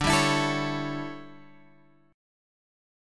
D Major 7th Suspended 2nd Suspended 4th